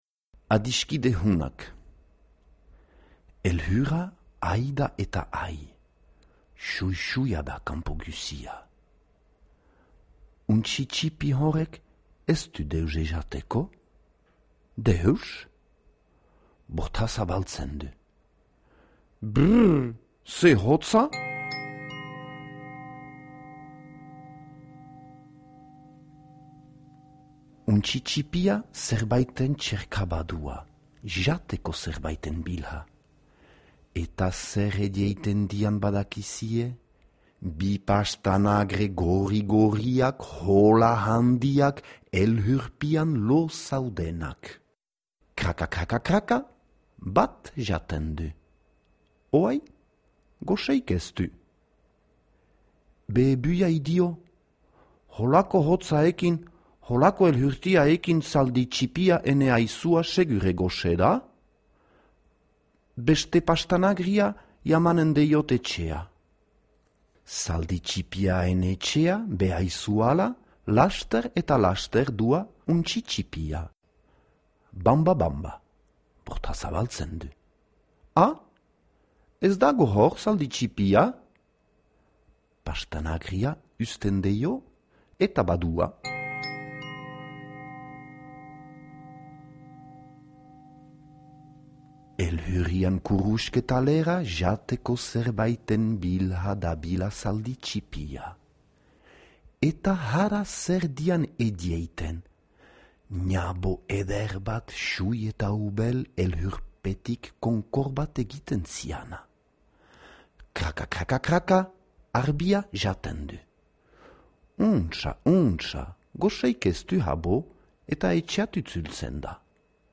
Adiskide honak - Zubereraz - ipuina entzungai